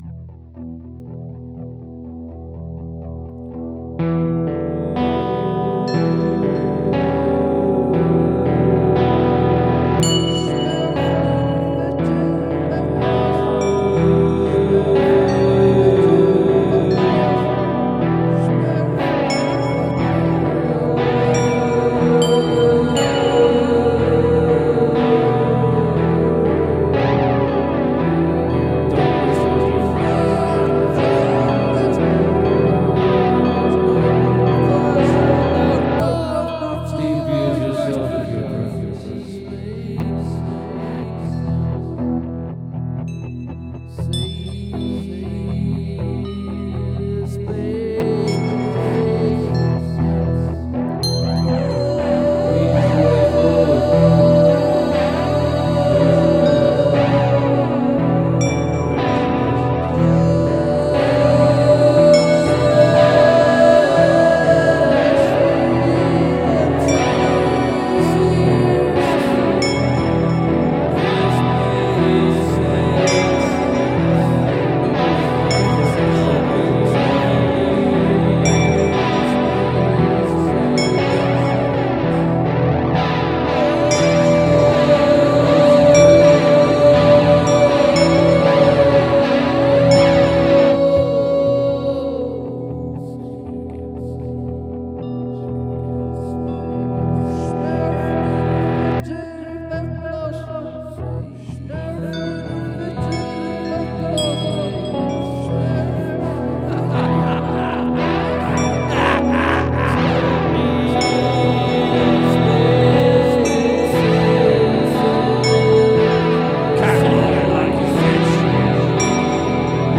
The disturbing voices and laughter.
Love all the swirliness and those tinkly percussion bits.
Guitar flangey stuff is very cool.
The percussion sound sounds a bit like a spoon with effects.
Love that guitar tone.